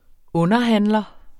Udtale [ ˈɔnʌˌhanlʌ ]